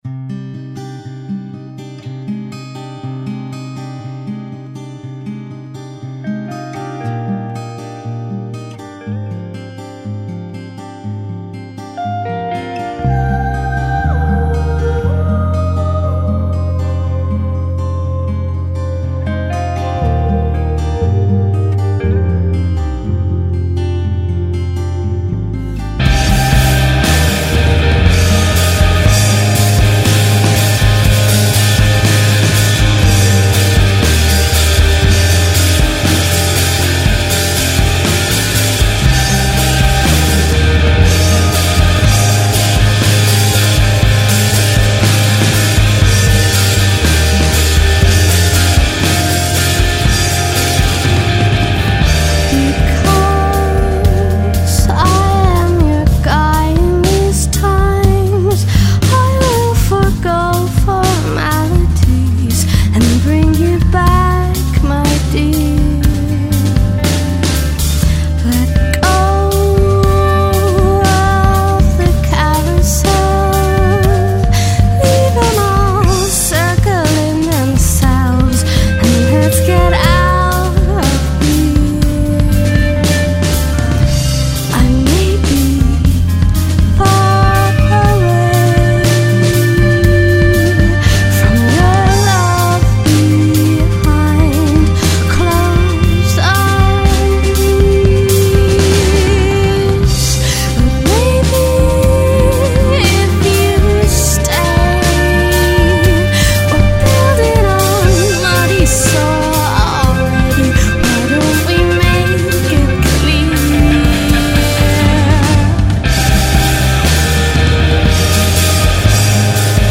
London based indie rock foursome